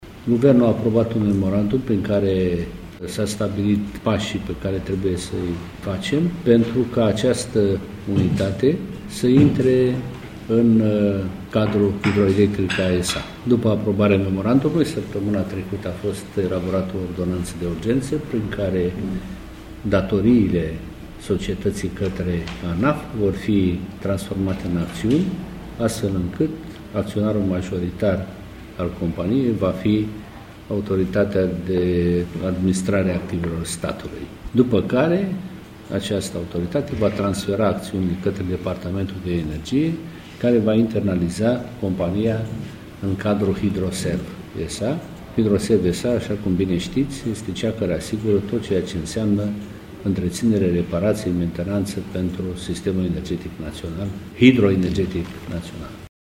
Despre motivele care au condus la preluarea de către stat a companiei, ministrul energiei a mai precizat: